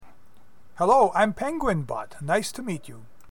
Can you make it take a bow (or some other custom gesture) while it speaks the "hello, nice to meet you" message?
0008_hello.mp3